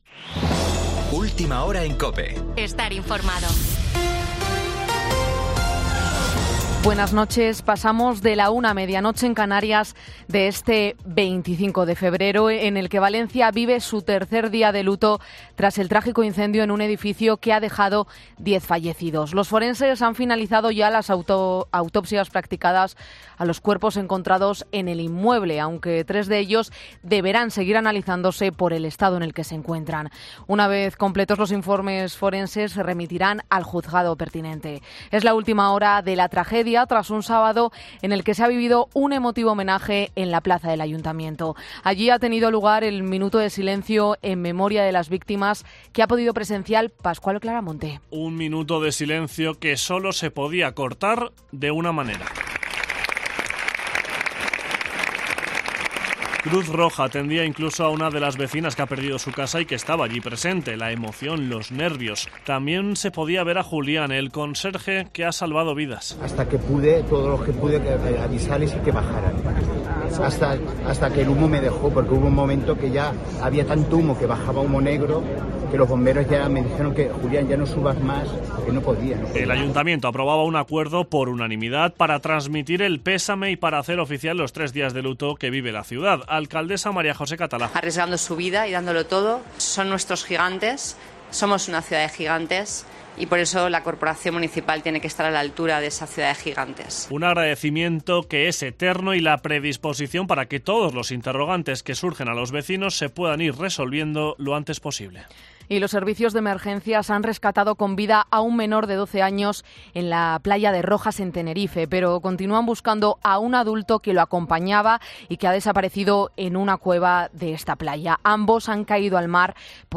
Boletín 01.00 horas del 25 de febrero de 2024